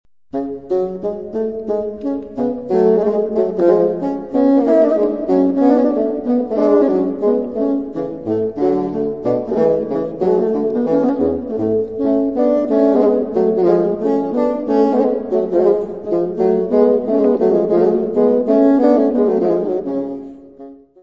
fagote
Music Category/Genre:  Classical Music
XIIIe concert pour deux bassons en sol majeur
Air.